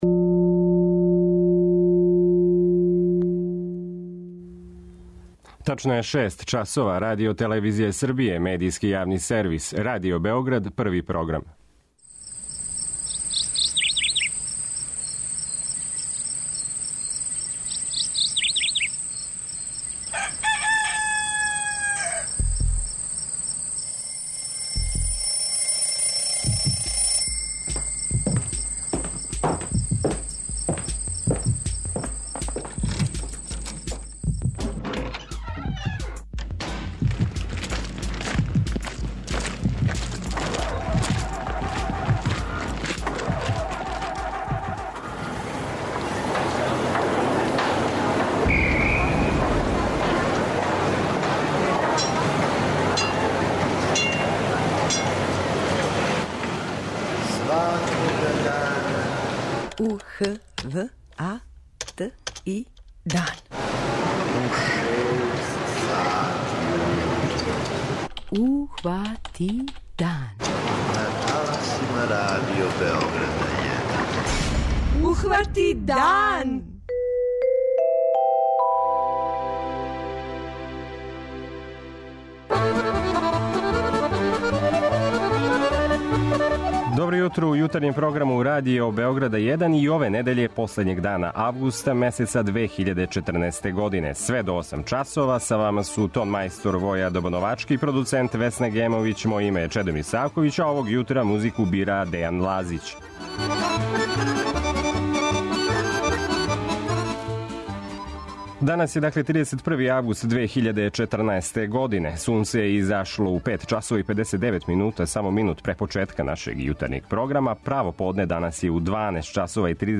преузми : 57.24 MB Ухвати дан Autor: Група аутора Јутарњи програм Радио Београда 1!